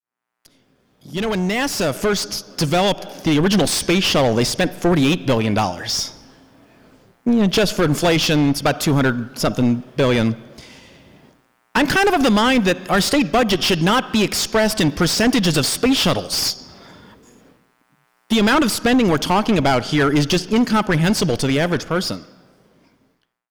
Lawmaker sounds off on new Missouri budget